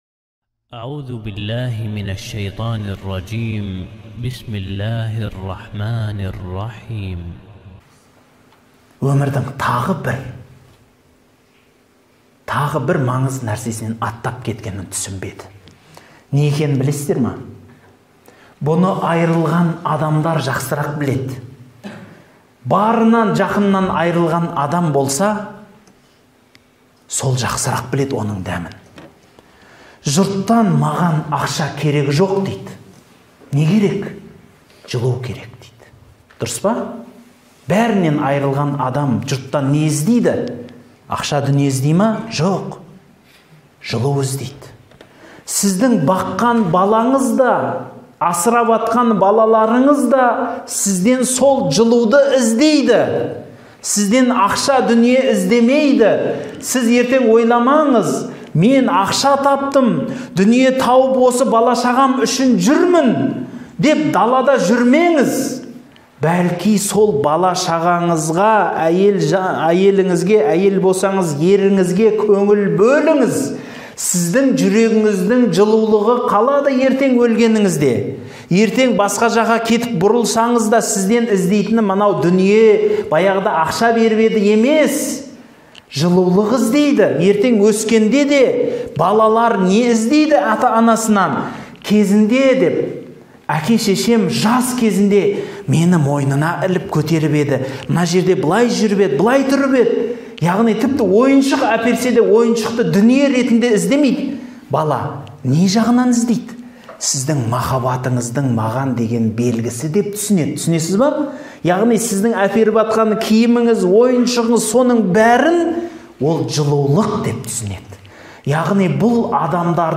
Сәдуақас қажы Ғылмани мешітінің ресми сайты